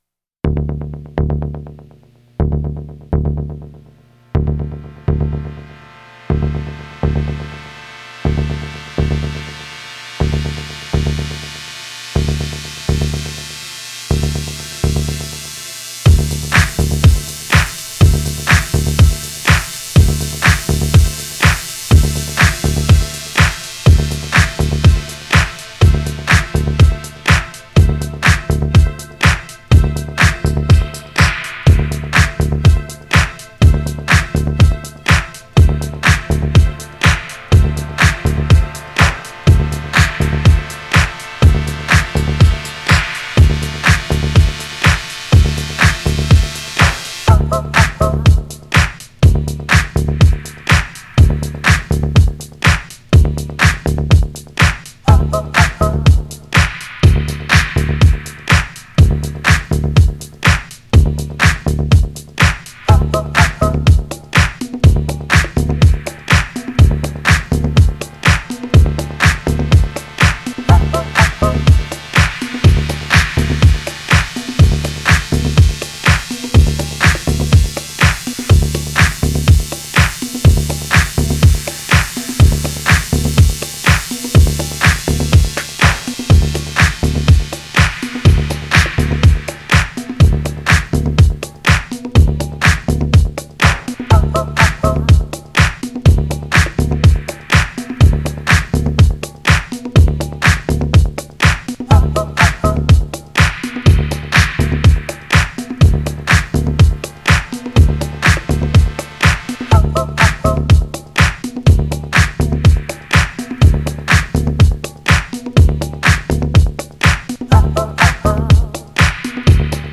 Filed under bangers, electronic